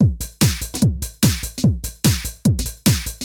Nice selection of drum loops.
HardTranceBeat.mp3